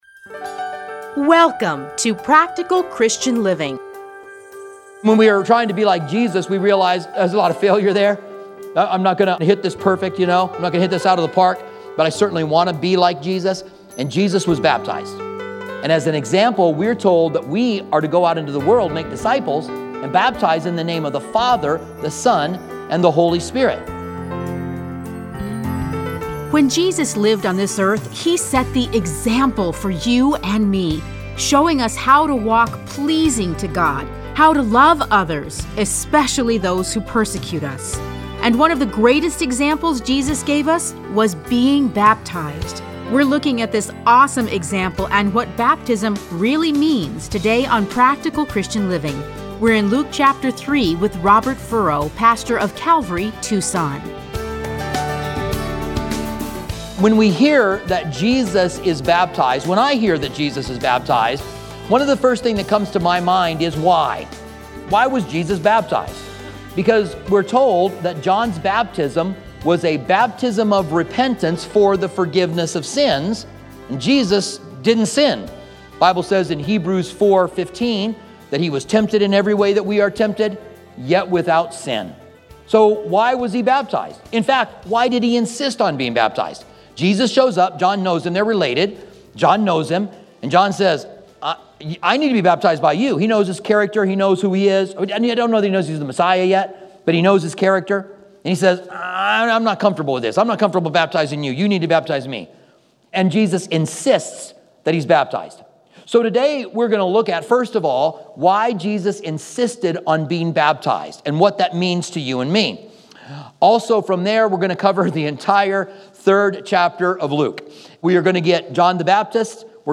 Listen to a teaching from Luke 3.